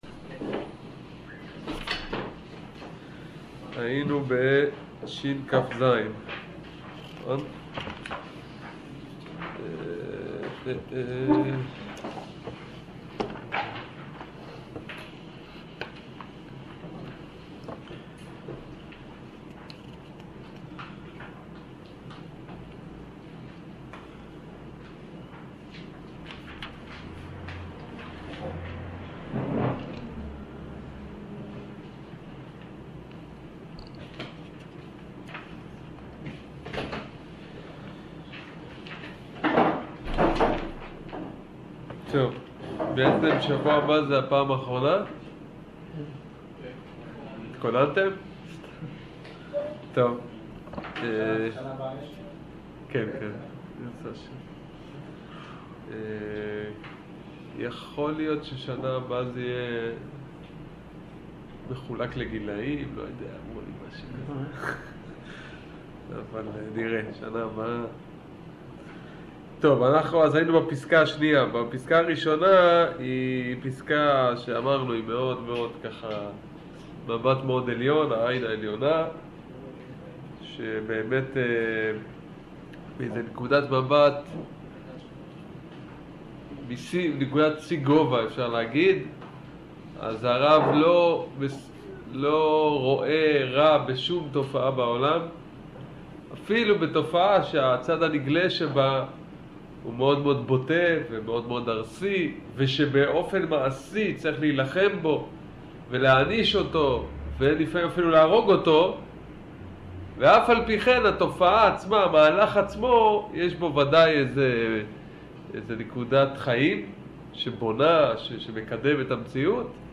שיעור פסקה י"ג